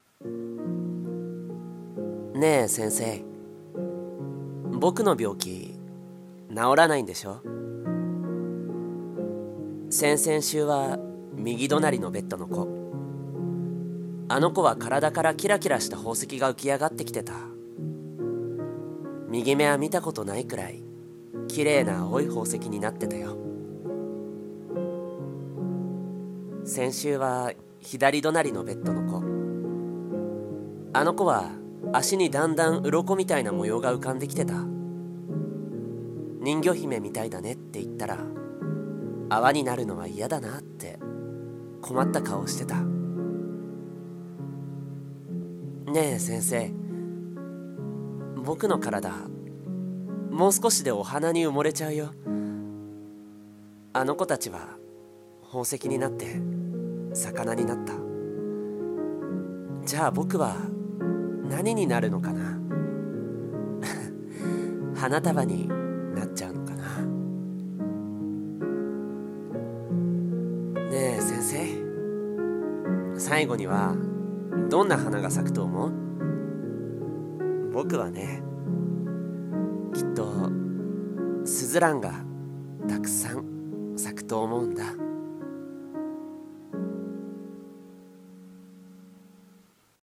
【一人声劇】スズランの花束【台本】 声劇